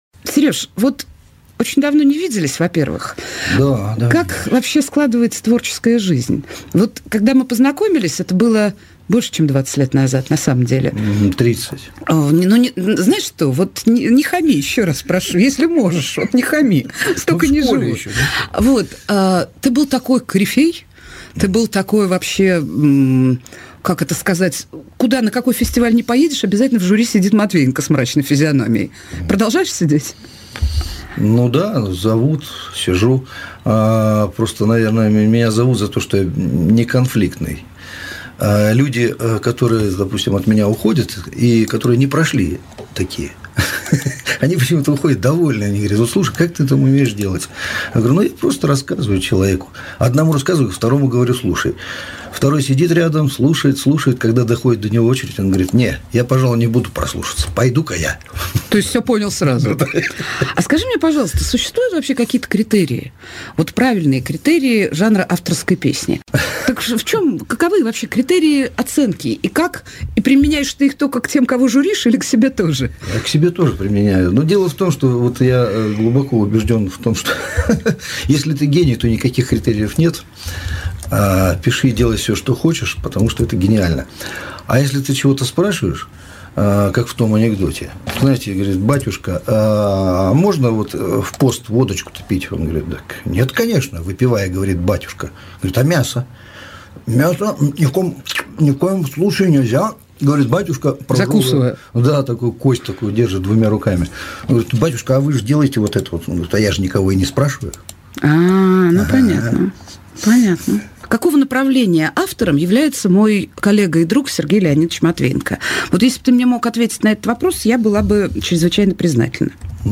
Нателла Болтянская беседует с известным бардом